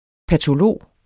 Udtale [ patoˈloˀ ]